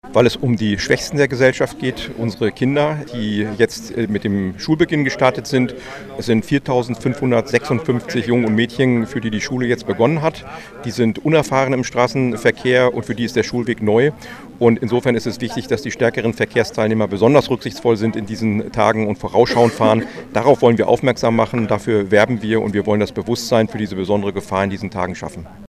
Landrat Martin Sommer über "Kreis Steinfurt sieht gelb!"